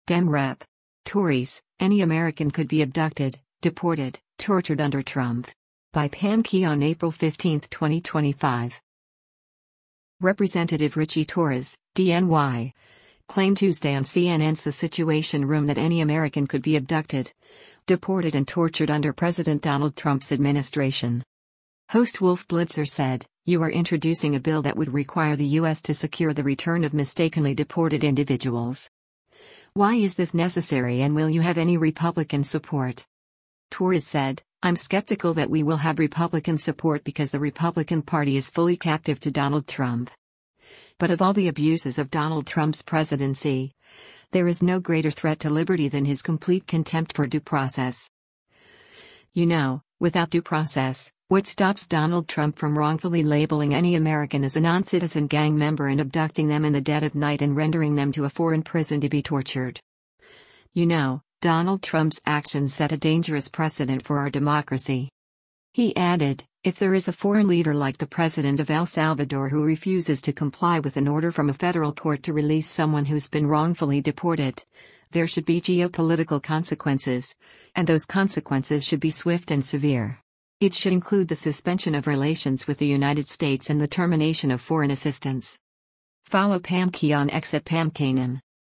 Representative Ritchie Torres (D-NY) claimed Tuesday on CNN’s “The Situation Room” that “any American” could be abducted, deported and tortured under President Donald Trump’s administration.